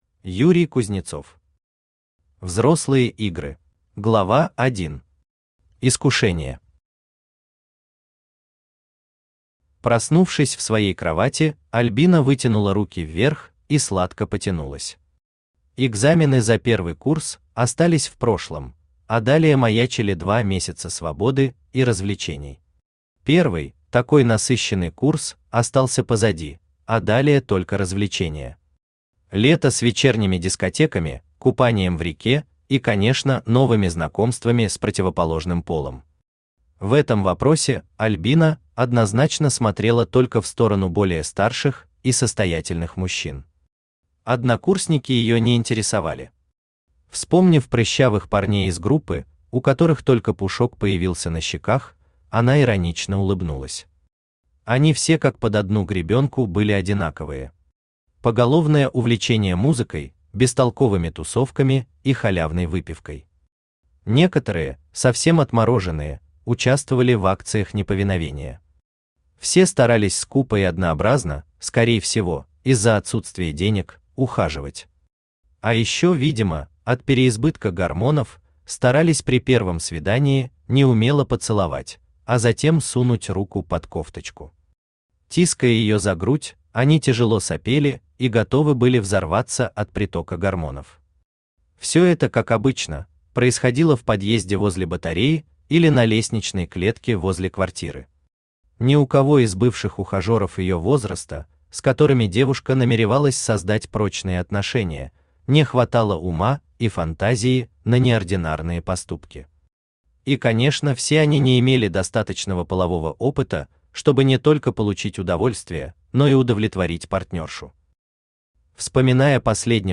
Aудиокнига Взрослые игры Автор Юрий Юрьевич Кузнецов Читает аудиокнигу Авточтец ЛитРес.